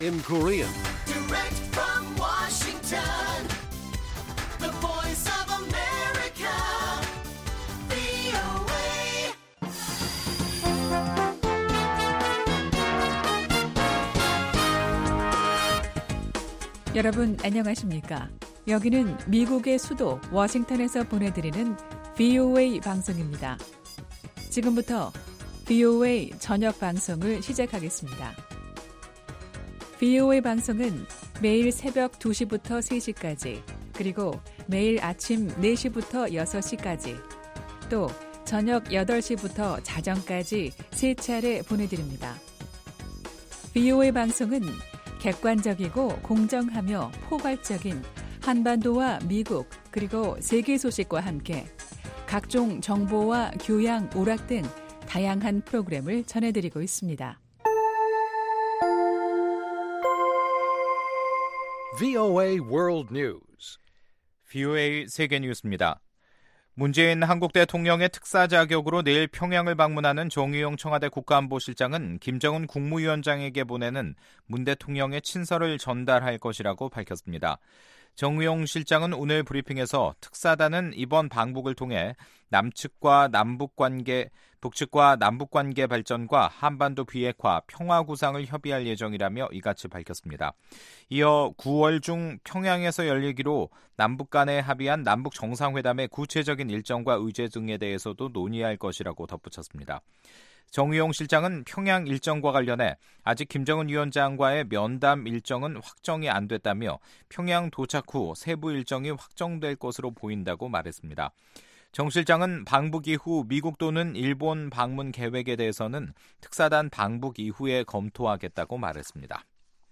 VOA 한국어 간판 뉴스 프로그램 '뉴스 투데이', 2018년 9월 4일 1부 방송입니다. 한국 청와대가 다음달 5일 북한에 대통령 특사를 파견한다고 밝혔습니다.